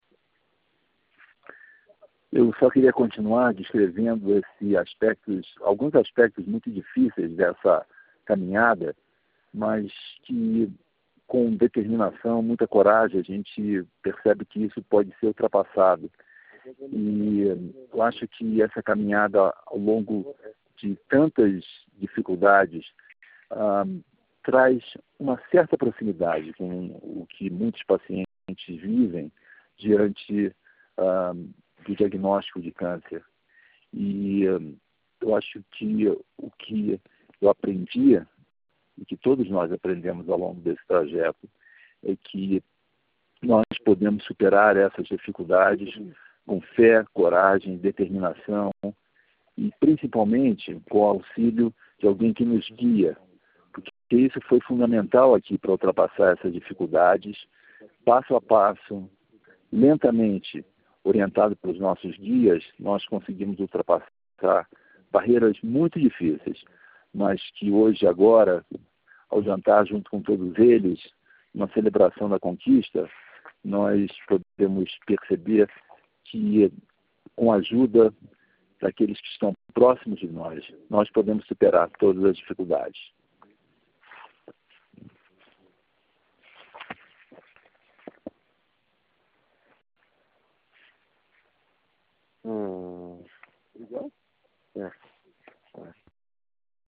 call